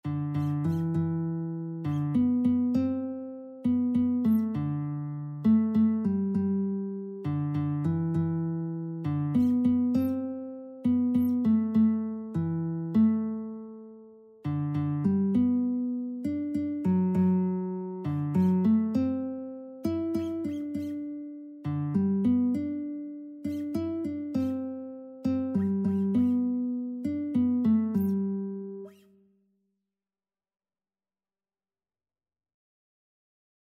Christian
3/4 (View more 3/4 Music)
Classical (View more Classical Lead Sheets Music)